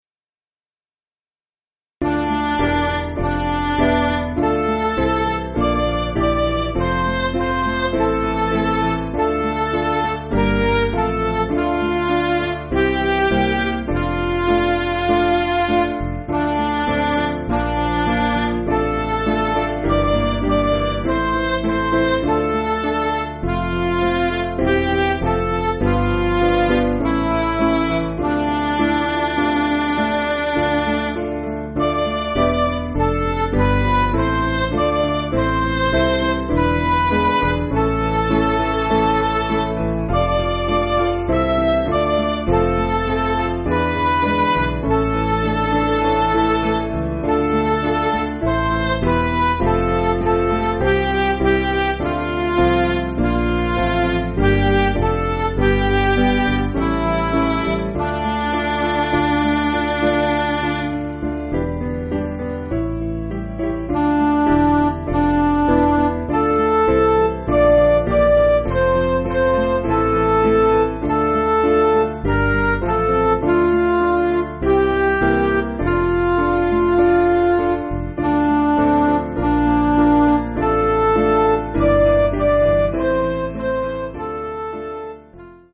Piano & Instrumental
(CM)   3/Dm